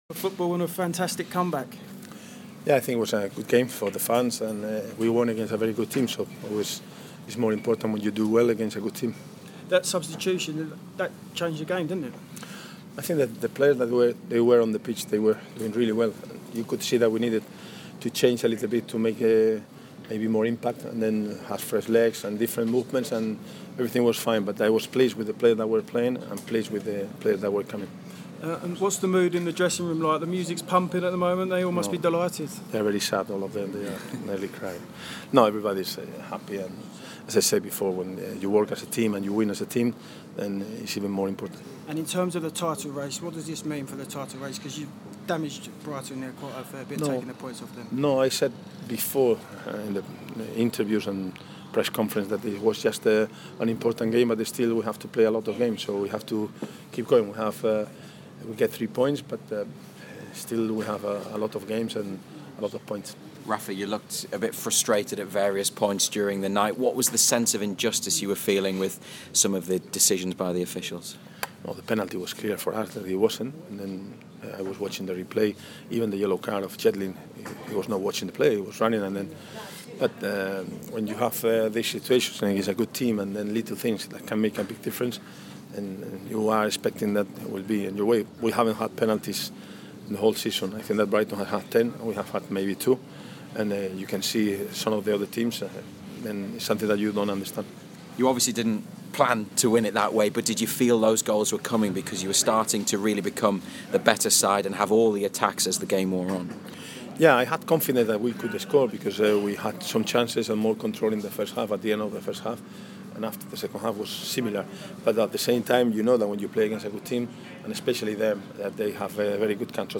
Rafa Benítez spoke to BBC Five Live and BBC Newcastle after the Magpies won 2-1 at the Amex Stadium.